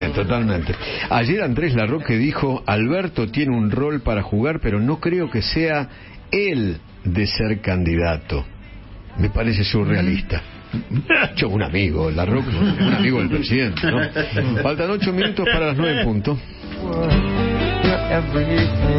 El conductor de Alguien Tiene Que Decirlo se refirió a los dichos de Andrés Larroque acerca de Alberto Fernández y sostuvo irónicamente “me parece surrealista”.
comentario.mp3